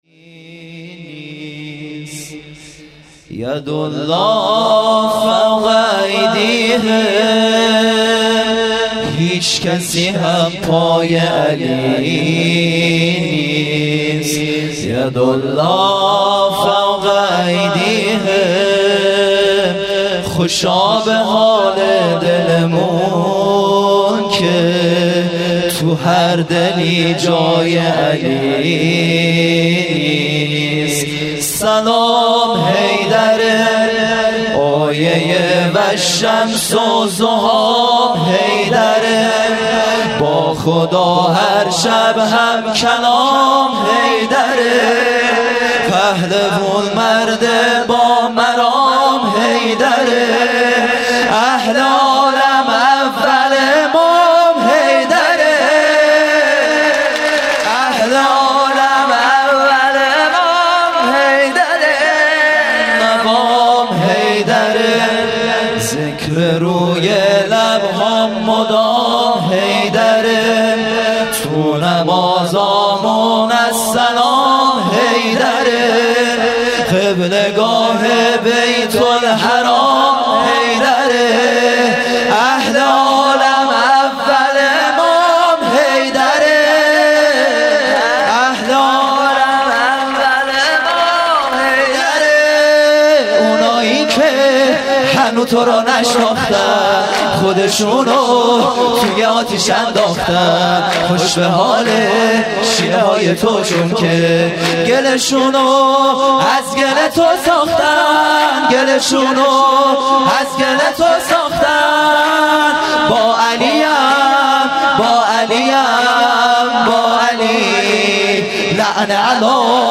خیمه گاه - هیئت بچه های فاطمه (س) - سرود | یدالله فوق ایدیهم